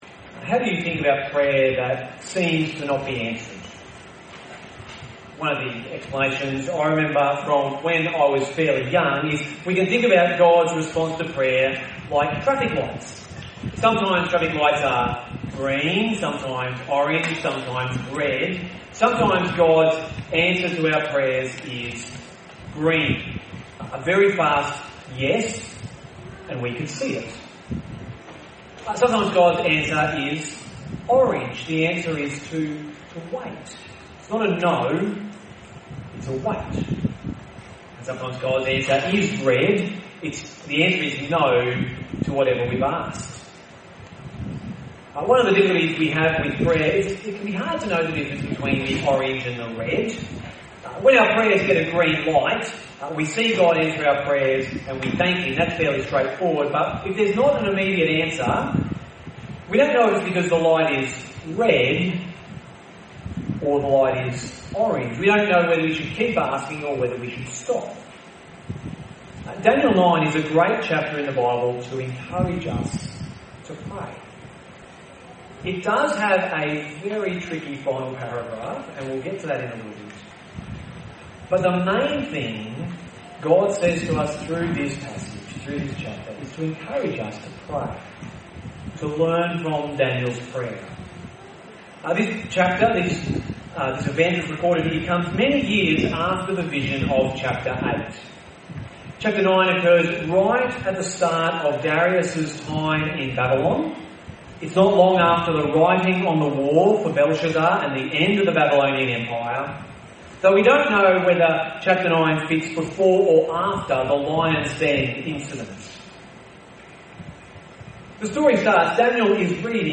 (N.b. this recording was from our live-streaming microphone and at times is difficult to understand.)